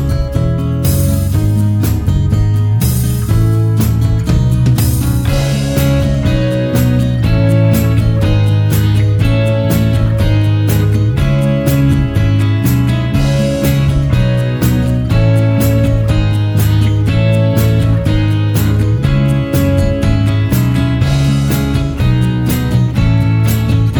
no Backing Vocals Indie / Alternative 4:17 Buy £1.50